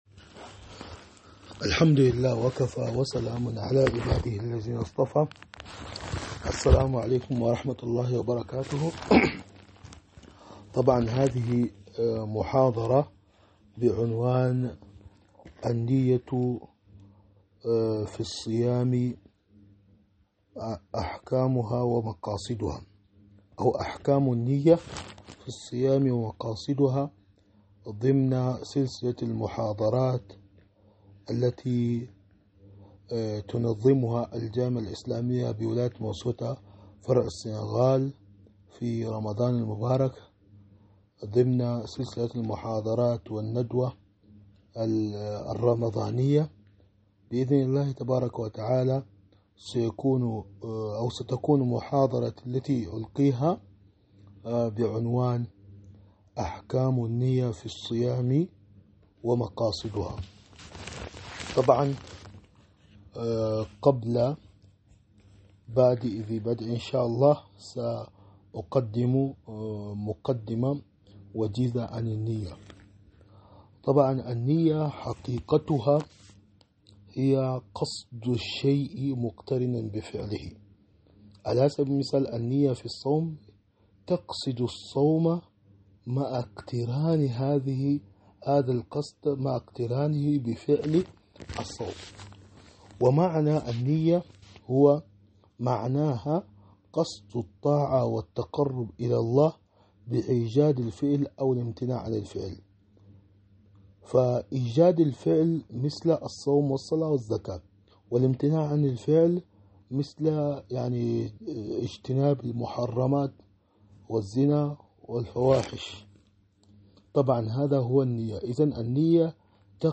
محاضرة أحكام النية في الصوم ومقاصدها